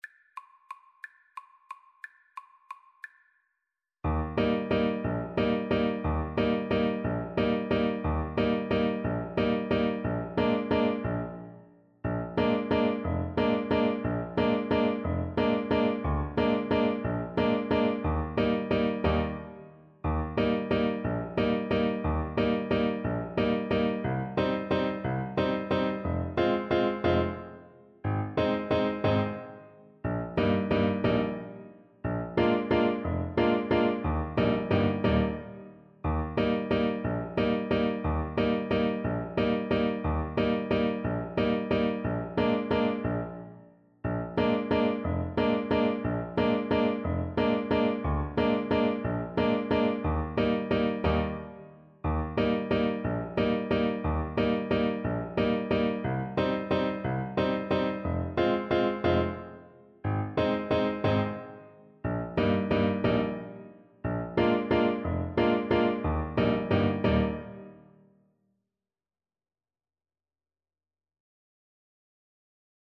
One in a bar .=c.60
3/4 (View more 3/4 Music)